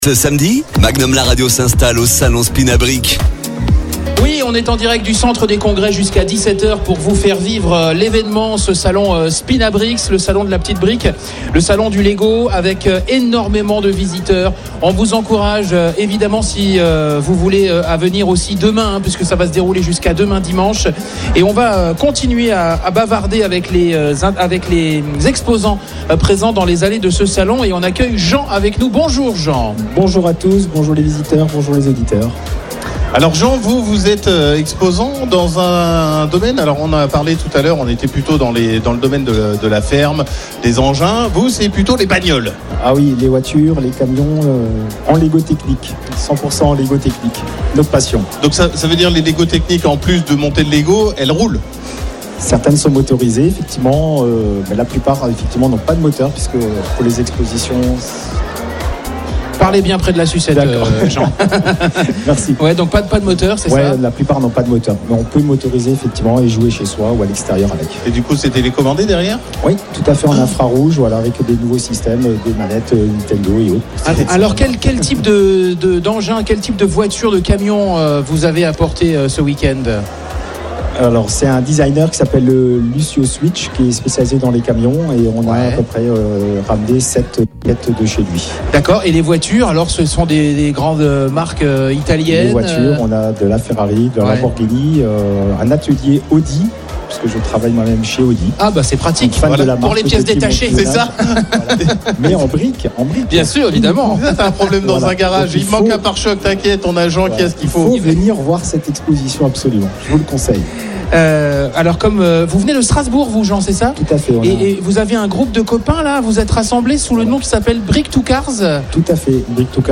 Emission spéciale en direct du salon SPINABRICKS au centre des congrès d'Épinal
Interview